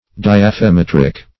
Search Result for " diaphemetric" : The Collaborative International Dictionary of English v.0.48: Diaphemetric \Di*aph`e*met"ric\, a. [Gr. dia` through + ? touch + ? measure.]